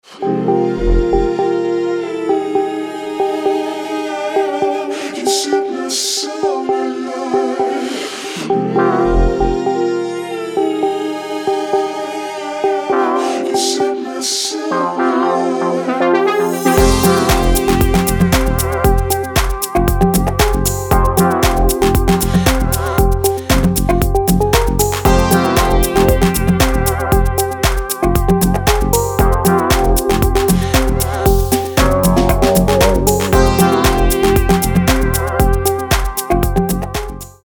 • Качество: 320, Stereo
ритмичные
мужской вокал
deep house
dance
спокойные
club
качающие
electro
vocal
Мелодичный спокойный рингтон